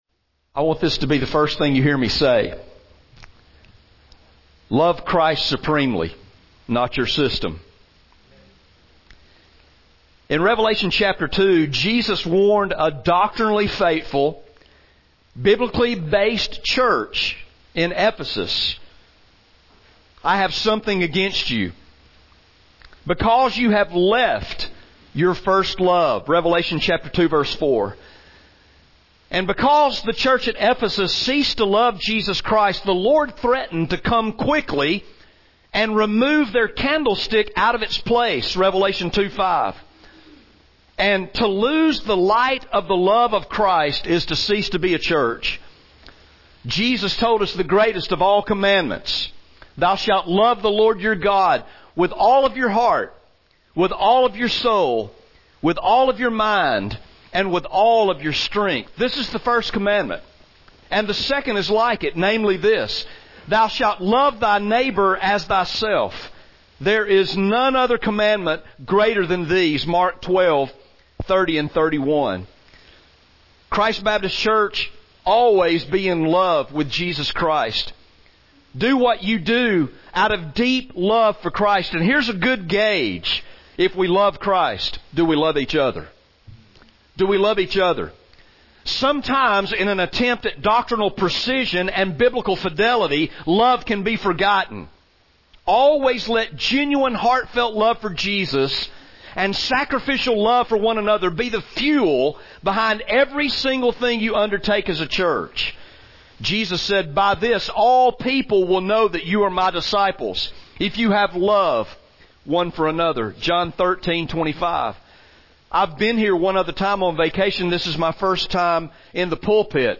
CBC Commissioning Service